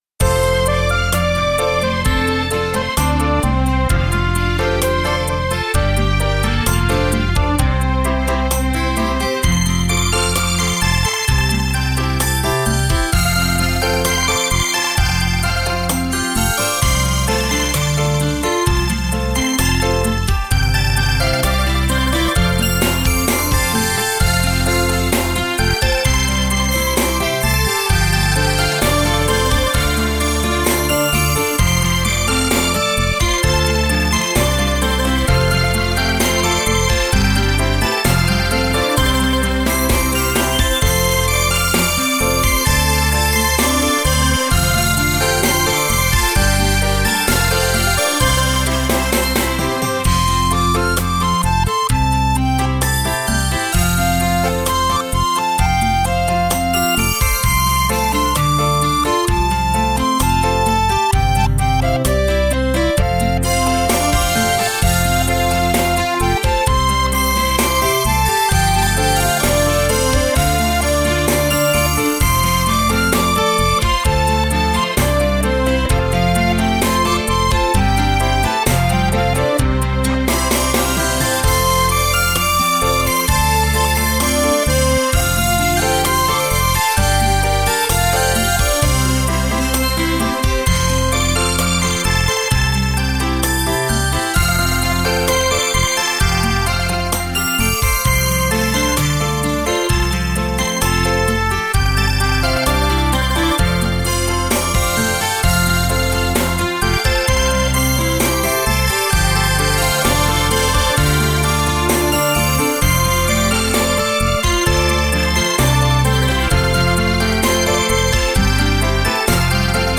纯净晶莹的乐曲 一如似水的岁月